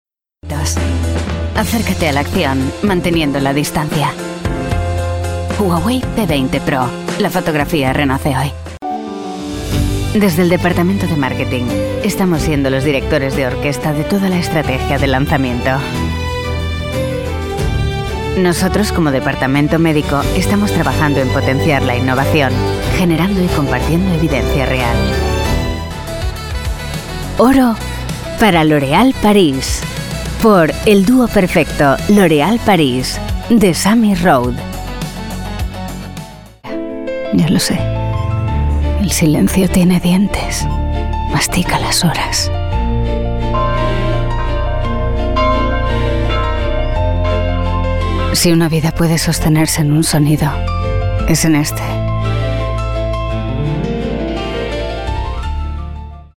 Versatile, Elegant, Sincere, Warm tones. 30-40.
Spanish showreel
Spanish Acting informative classic